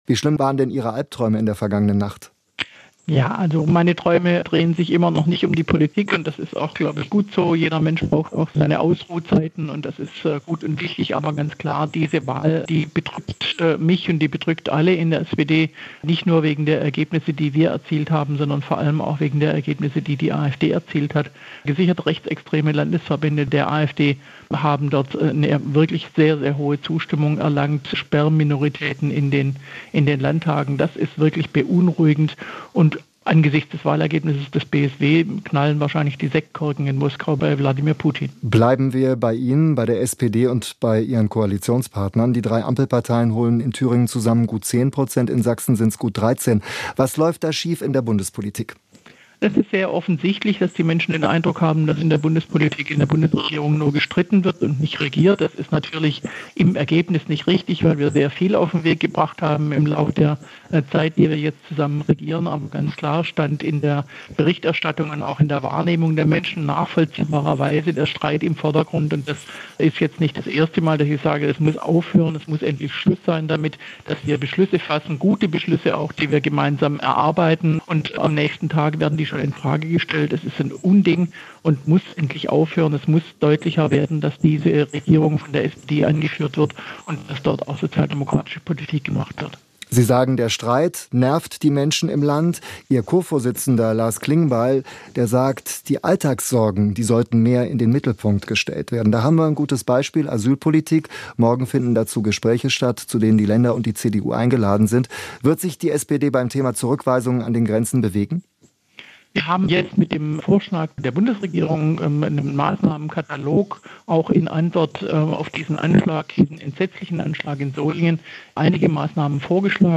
Die Menschen hätten den Eindruck, dass in der Bundesregierung nur gestritten würde, was nicht richtig sei. In SWR Aktuell sagte Esken, sie halte trotz des schlechten Abschneidens bei den Wahlen in Sachsen und Thüringen am SPD-Bundesvorsitz fest.